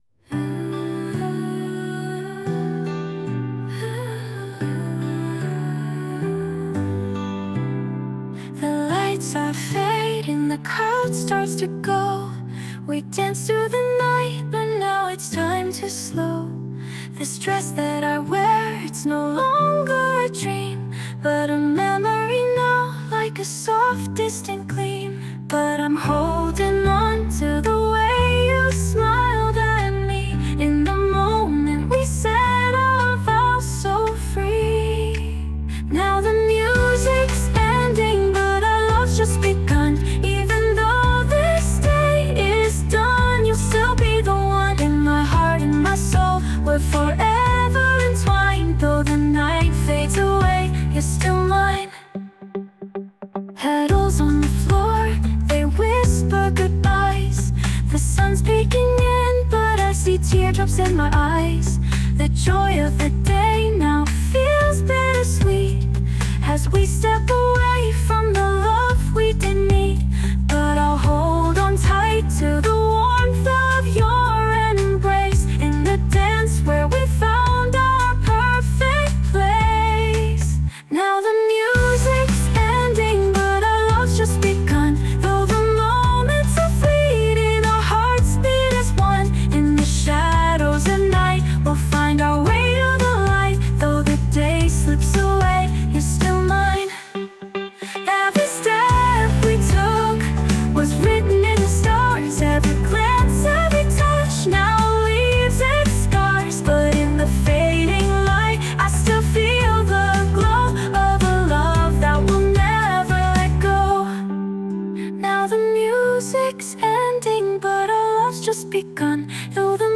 女性ボーカル洋楽 女性ボーカルエンドロール
著作権フリーBGMです。
女性ボーカル（洋楽・英語）曲です。